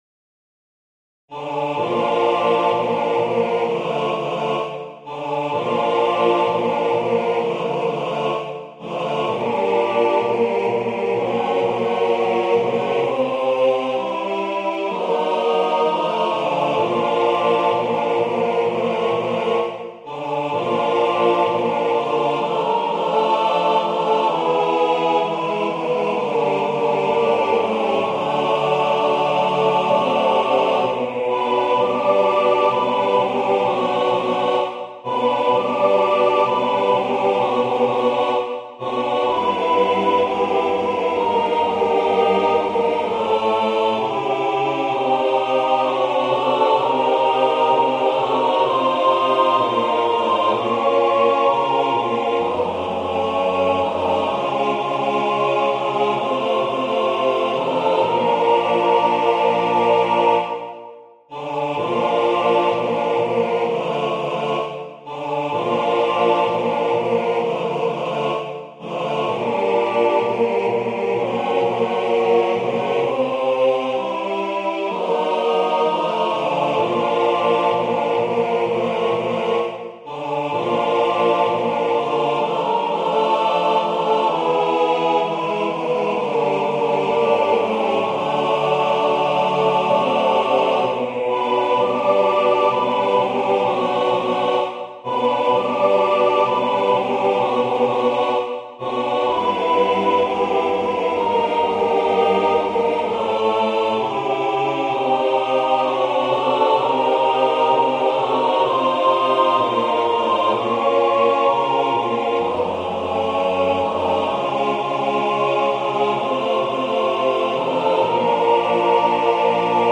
Ноты, хор, партитура голосов